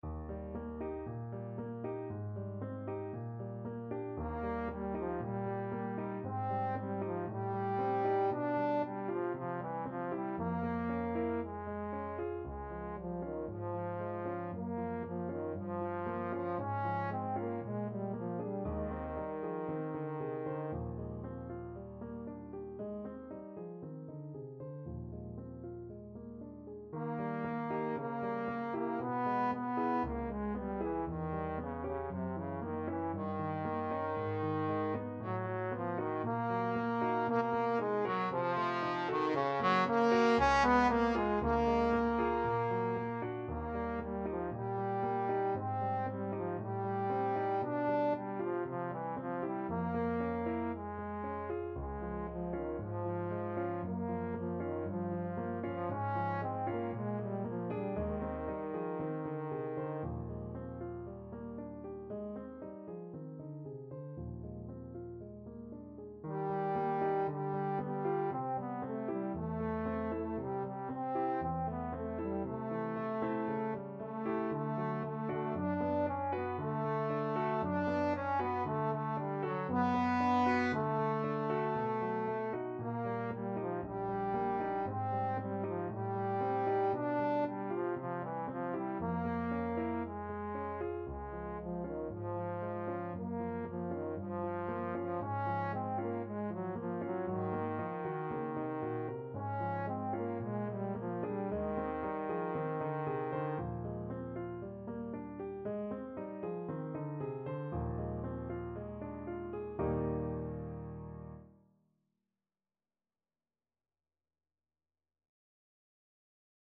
Trombone version
Allegro moderato =116 (View more music marked Allegro)
Trombone  (View more Intermediate Trombone Music)
Classical (View more Classical Trombone Music)